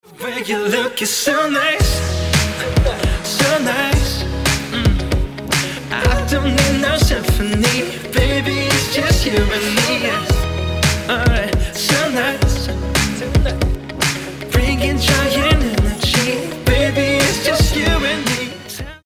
A fresh funk-infused pop song.